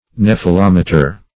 Nephelometer \Neph`e*lom"e*ter\
(n[e^]f`[-e]*l[o^]m"[-e]*t[~e]r), n. [Gr. nefe`lh a cloud +